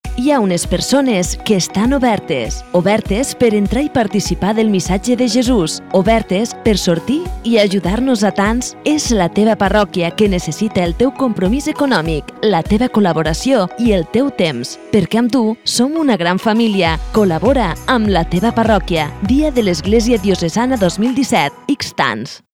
Cuñas de Radio -  Iglesia Diocesana 2017